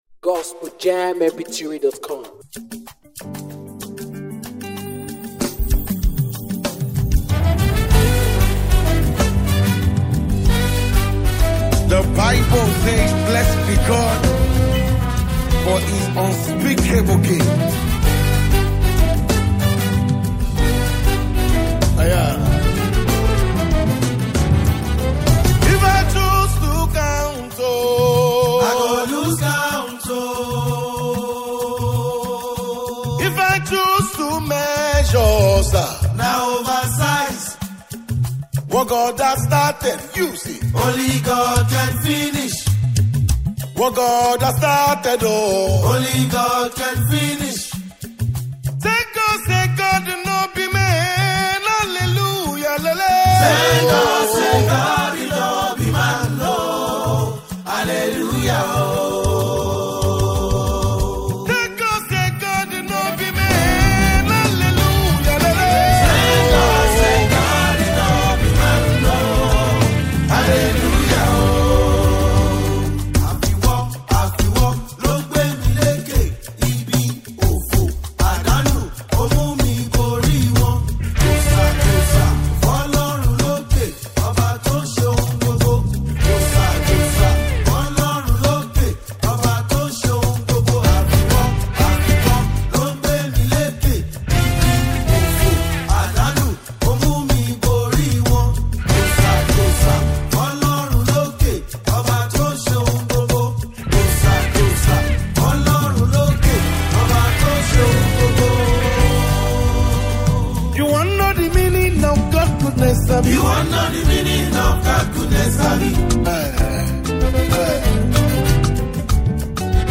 is a powerful and energetic gospel song